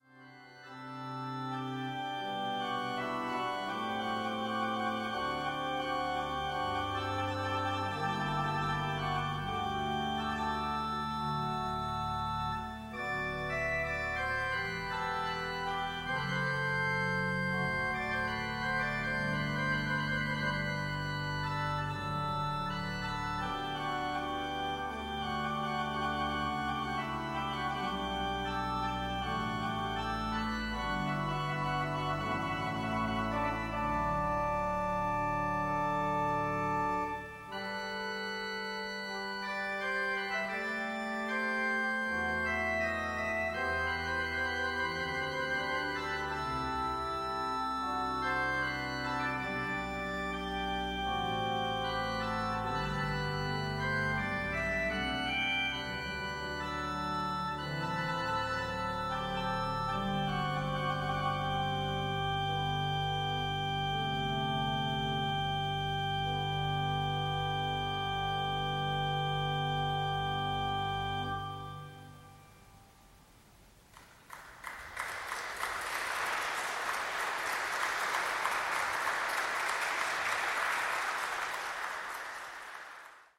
Aedian Skinner-Orgel der Riverside Church, New York